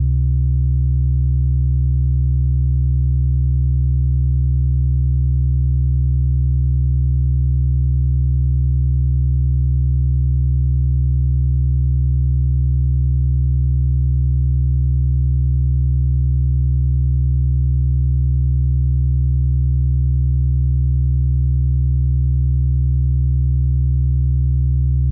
drone.ogg